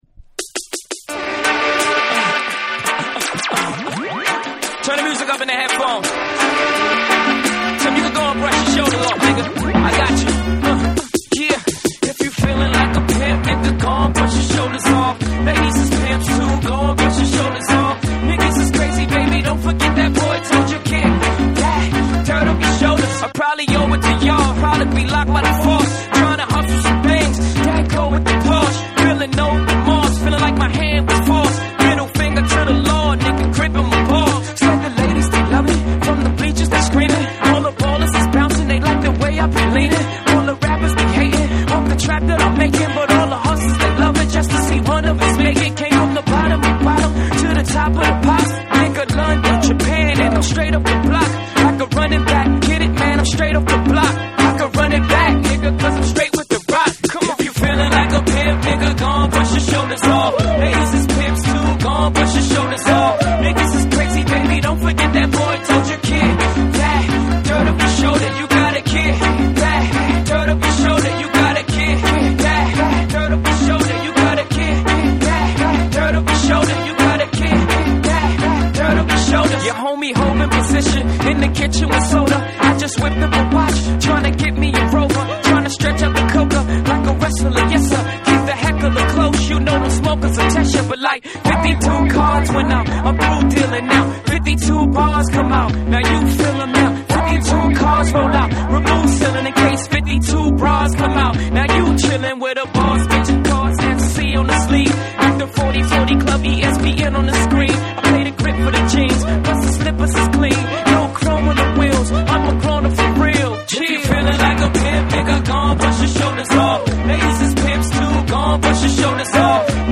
BREAKBEATS / REGGAE & DUB / RE-EDIT / MASH UP